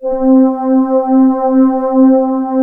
TGANALOG C5.wav